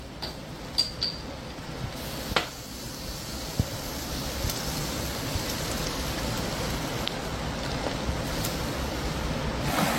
Kling-Audio-Eval / Human sounds /Hands /audio /19094.wav